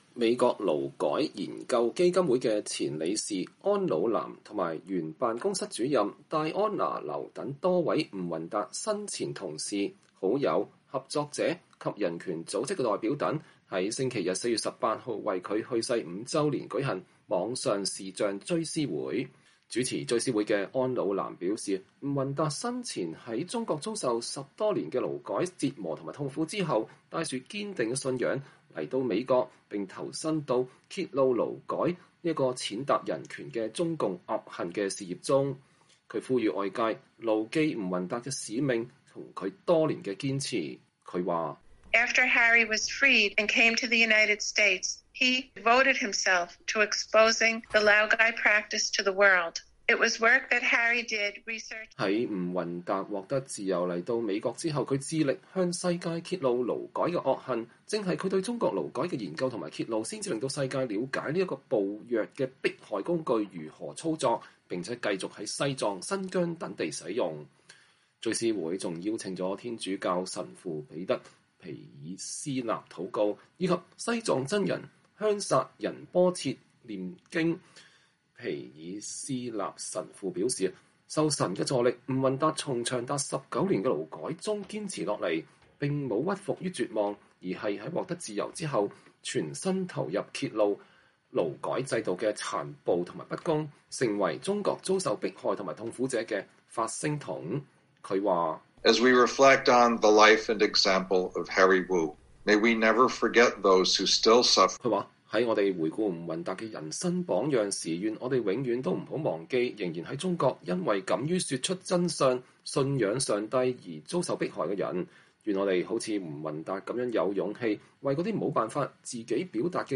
此外，藏人和維族人人權組織的代表也在追思會上發言，並介紹各自民族所遭受的人權迫害。